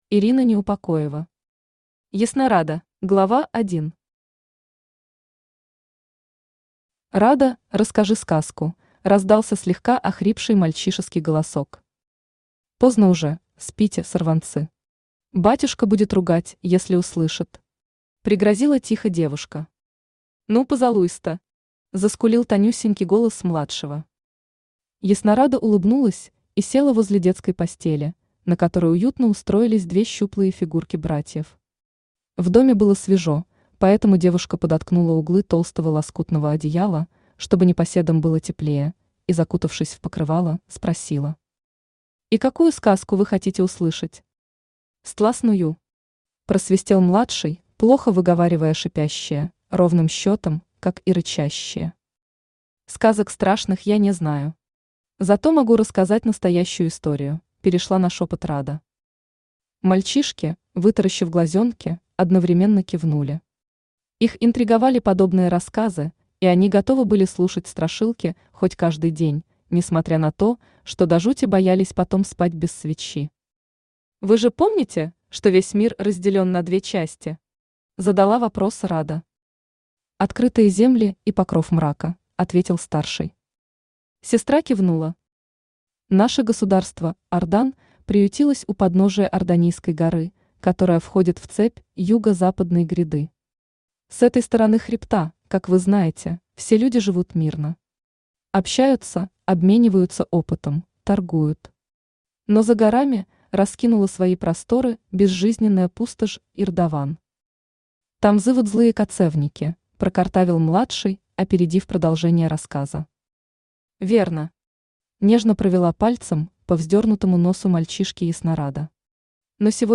Аудиокнига Яснорада | Библиотека аудиокниг
Aудиокнига Яснорада Автор Ирина Неупокоева Читает аудиокнигу Авточтец ЛитРес.